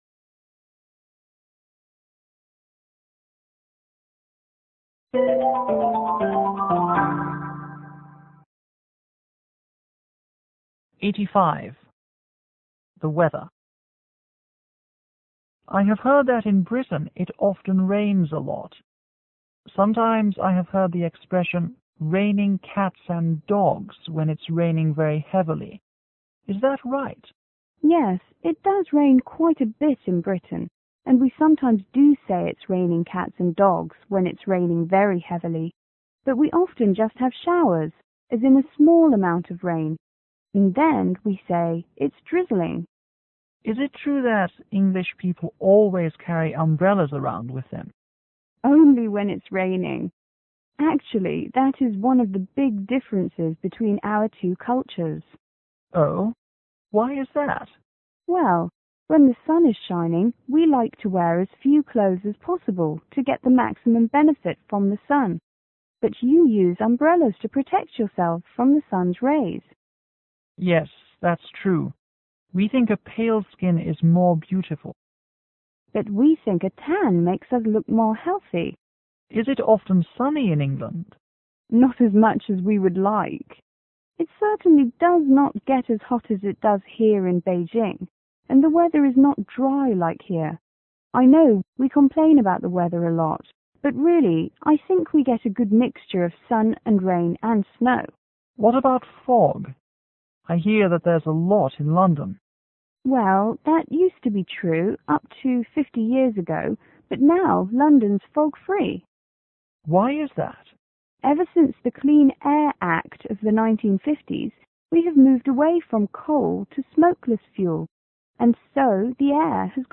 C:Chinese student    F: Foreigner